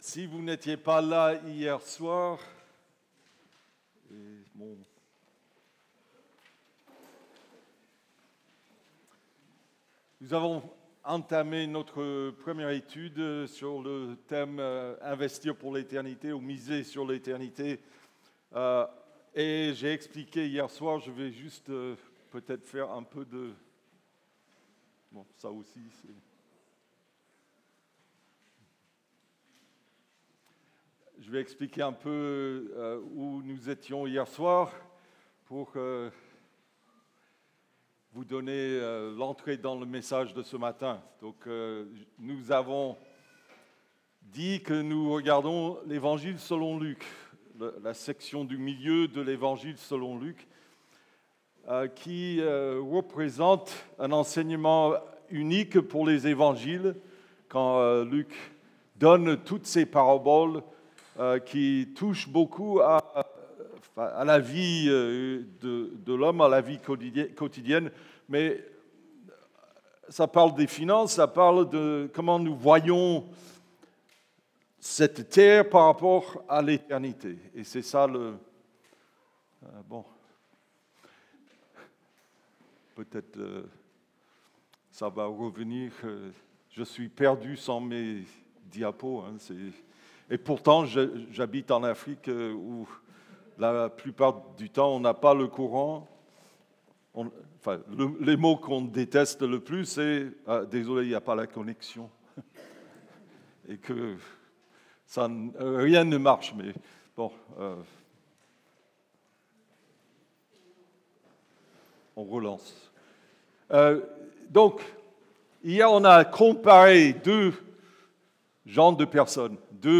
Culte du dimanche 14 Septembre 25 (2/4)
Prédications